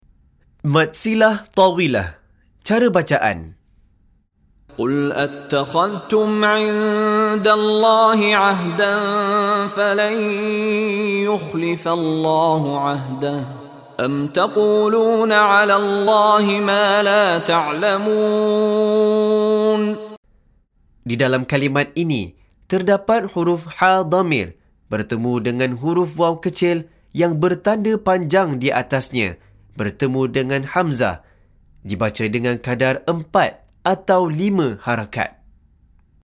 Penerangan Hukum + Contoh Bacaan dari Sheikh Mishary Rashid Al-Afasy
DIPANJANGKAN sebutan huruf Mad dengan kadar 4 atau 5 harakat (secara simbolik)